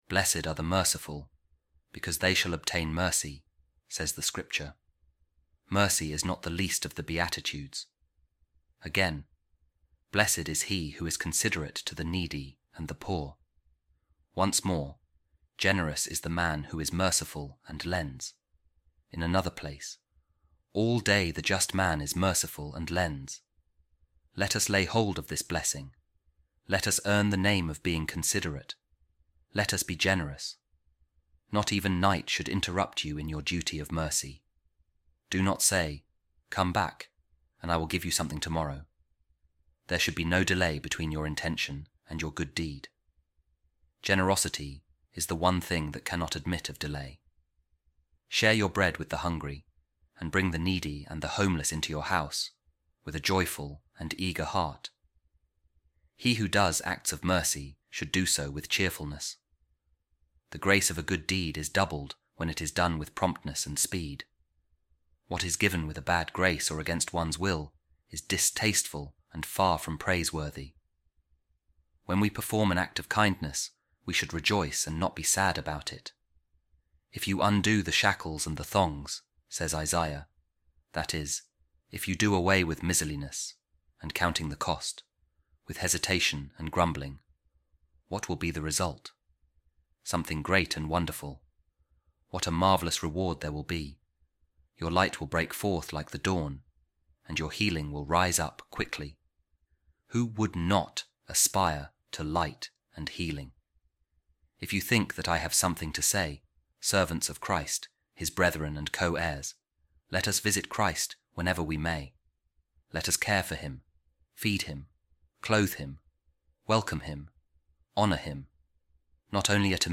A Reading From The Addresses Of Saint Gregory Nazianzen